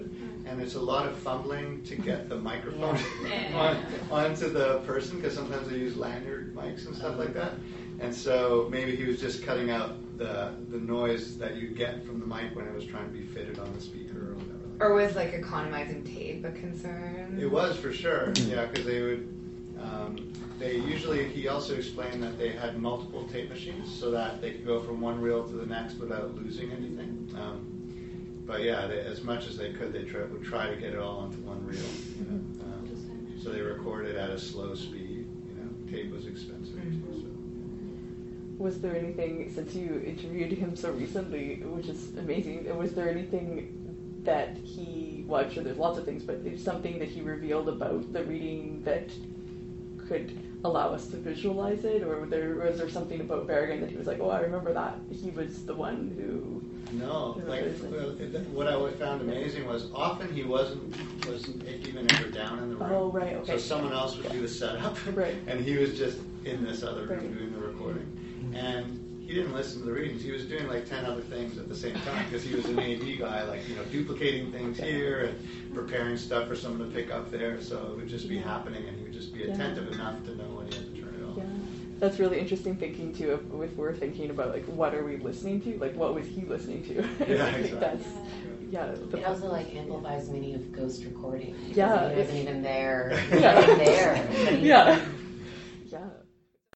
That clip of the critical debrief includes sounds of our ongoing book-making while talking, along with our speculation about how the technical side of the audio was captured, or not, by the recording technician (“what was he listening to” and “was he even there?!”).
As someone who was in the room and was part of the conversation, I would say yes and no. The recording device was visibly present in the room with us but we spoke candidly and as though it wasn’t there.